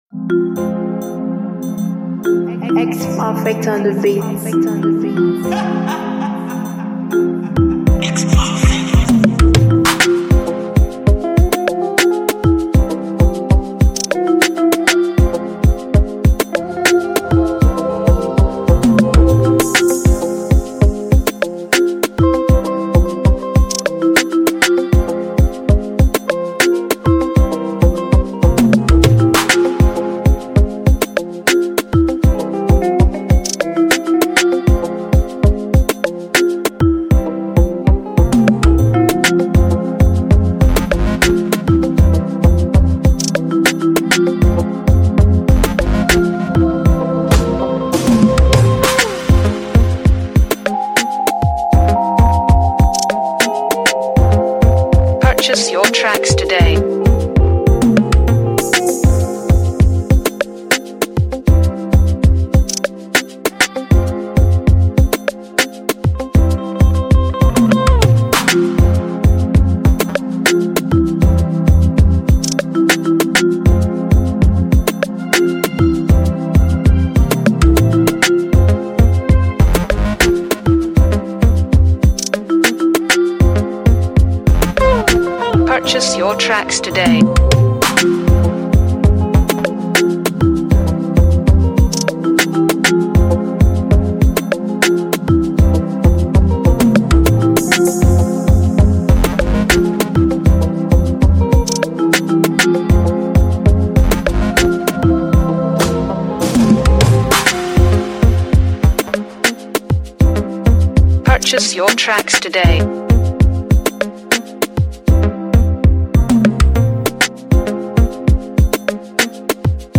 2022-06-30 1 Instrumentals 0
Download instrumental mp3 below…